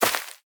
Minecraft Version Minecraft Version snapshot Latest Release | Latest Snapshot snapshot / assets / minecraft / sounds / block / rooted_dirt / step5.ogg Compare With Compare With Latest Release | Latest Snapshot
step5.ogg